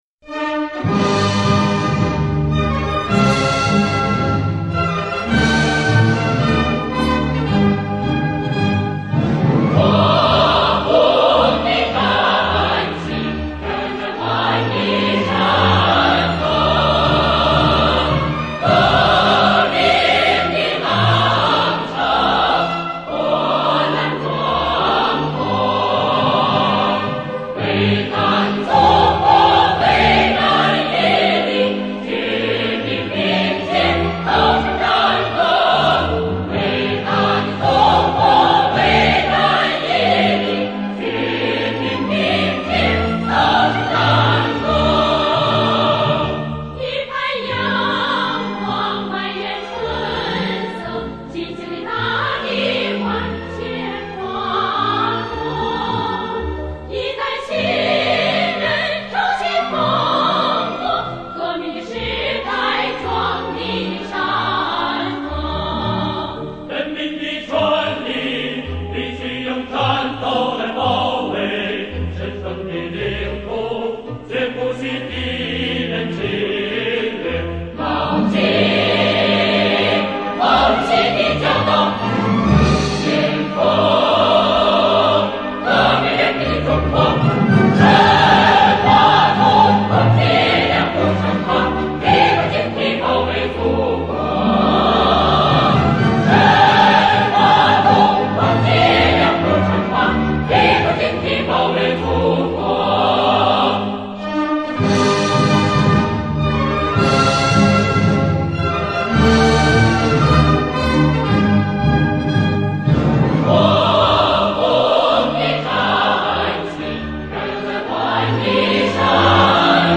[21/4/2009]提高警惕、保卫祖国（合唱）